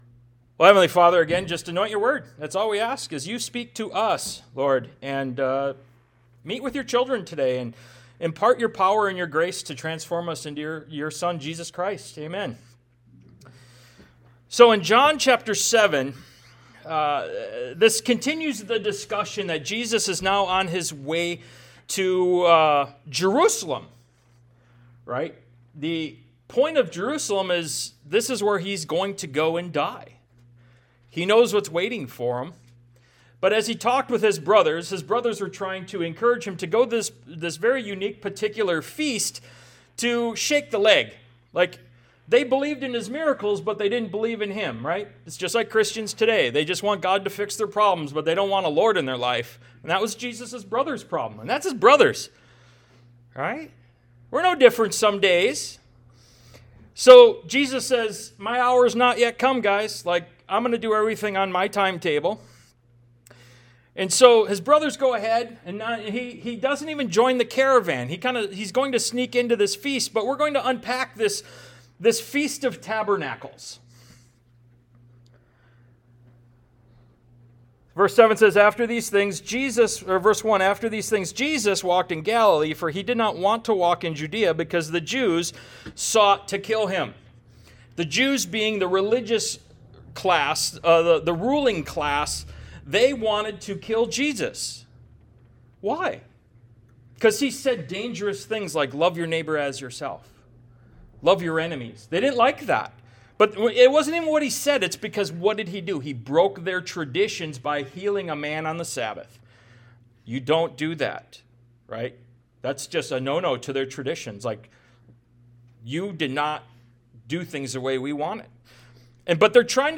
Ministry of Jesus Service Type: Sunday Morning « “Excuses or Reasons?”